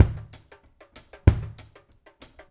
ABO DRM-SN-R.wav